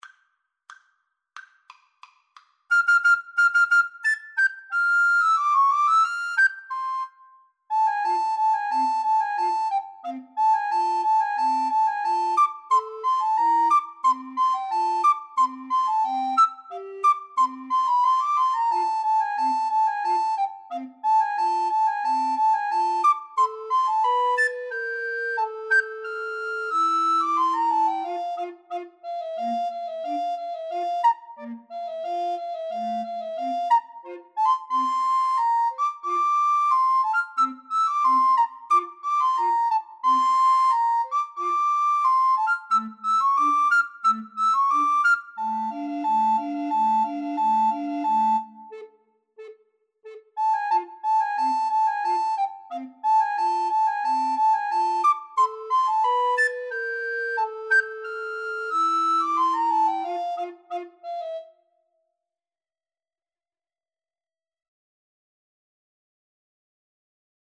Allegro =180 (View more music marked Allegro)